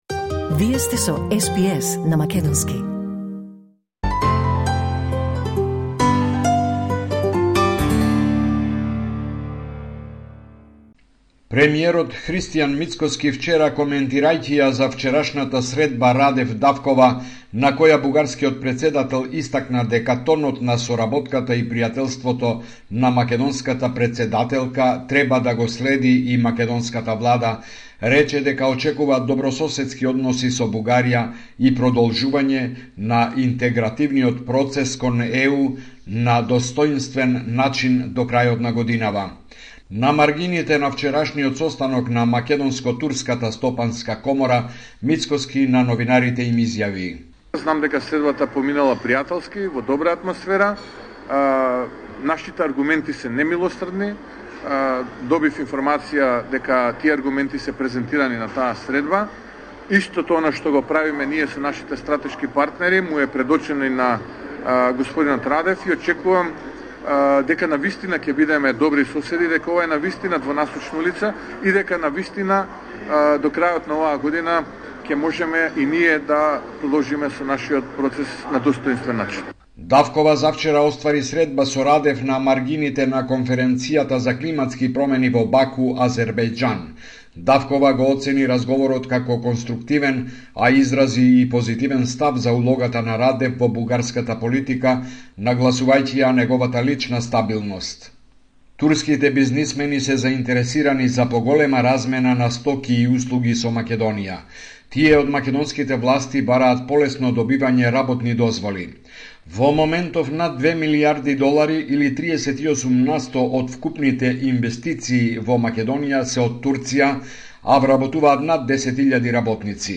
Извештај од Македонија 15 ноември 2024